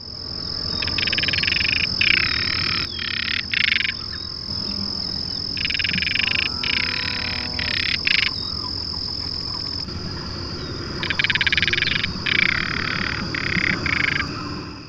Crested Caracara
Caracara cheriway